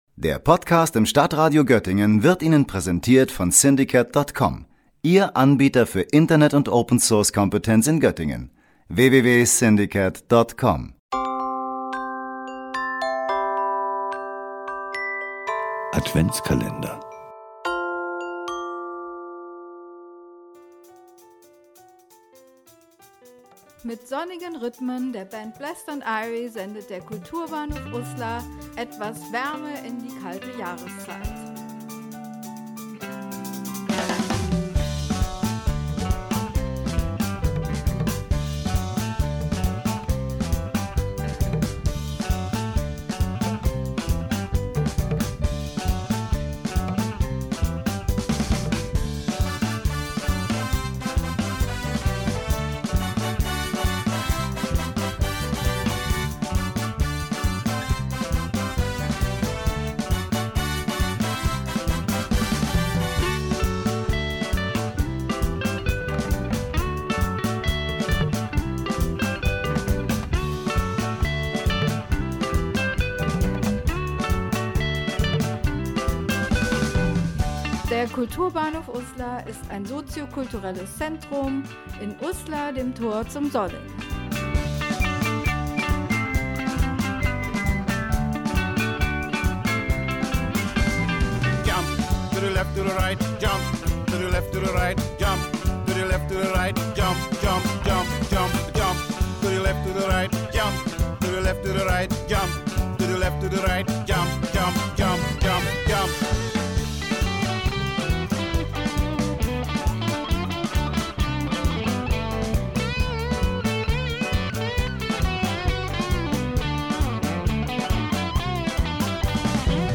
Türchen 20: Reggae zu Weihnachten